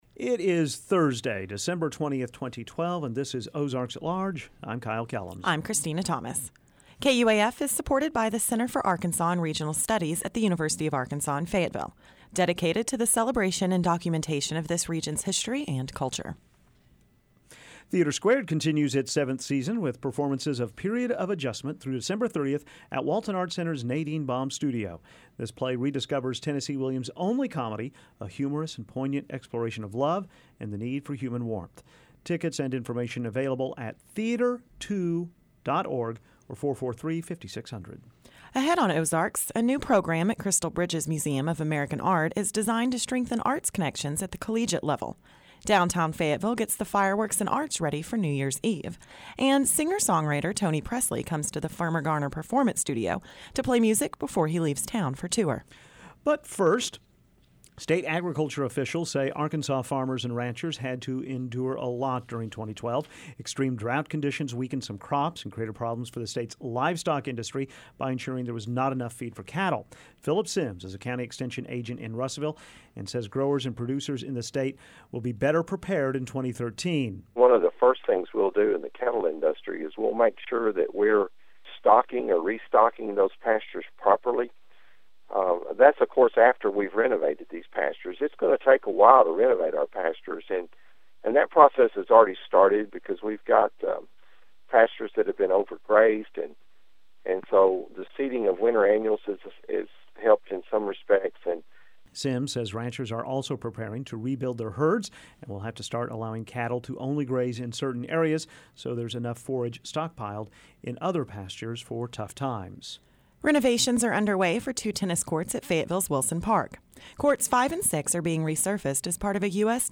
comes to the Firmin Garner Performance Studio to play music before he leaves town for tour.